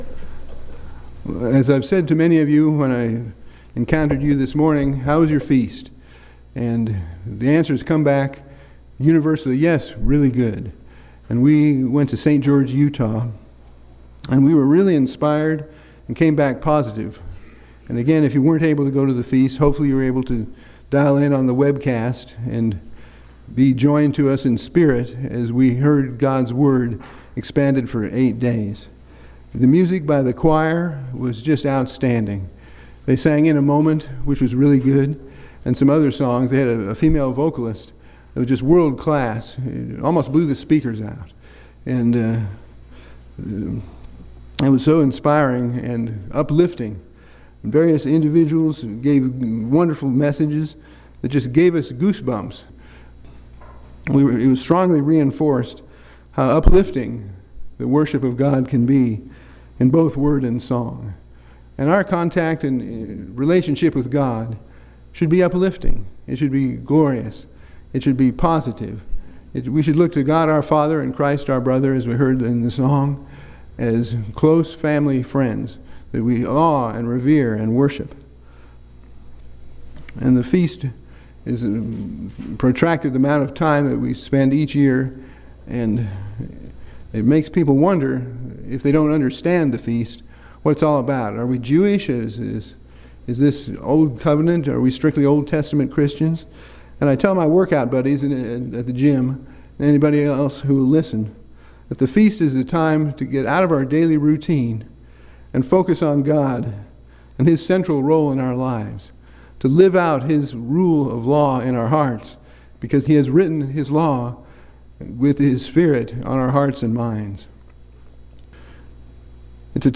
This sermon gives us help on achieving this.
Given in Ft. Wayne, IN